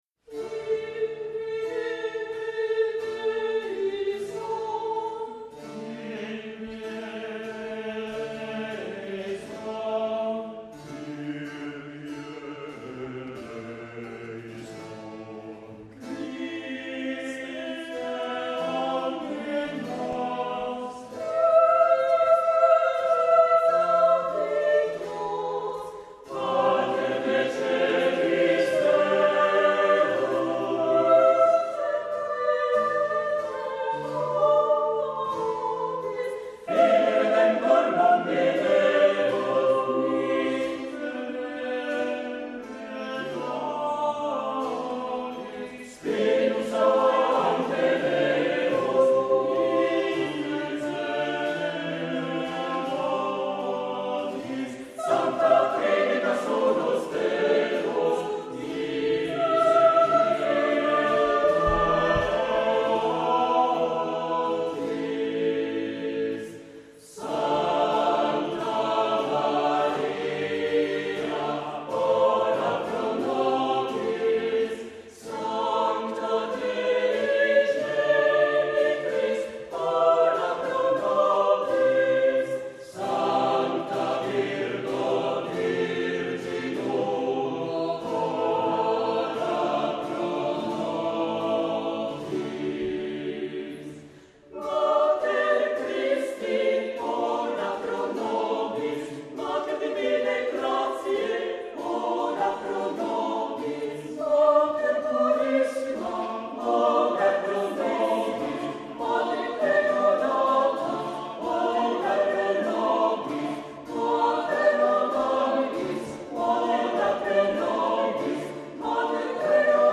primo violino
secondo violino
viola
violoncello
contrabbasso
cembalo
GenereCori